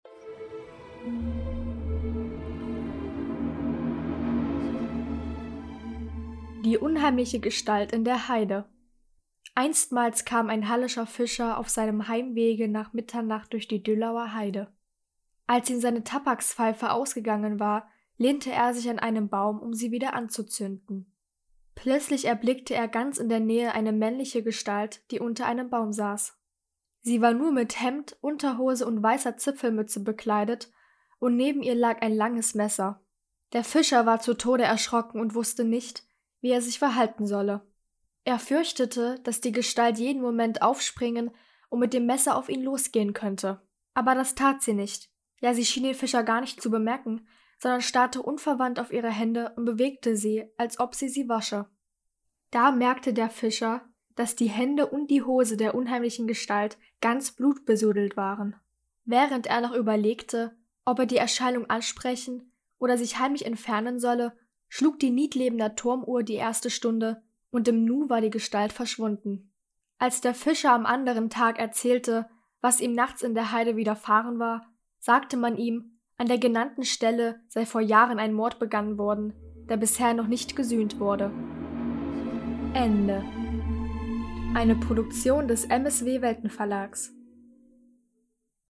Sagen aus der Umgebung von Halle (Saale), gelesen von der